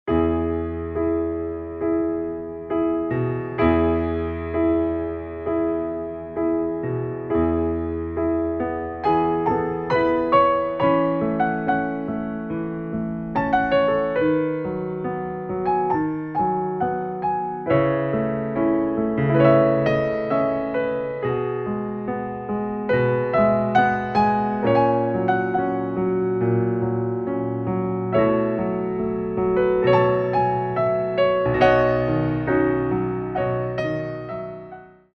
33 Inspirational Ballet Class Tracks
Warm Up
4/4 (16x8)